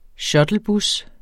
Udtale [ ˈɕʌdlˌbus ]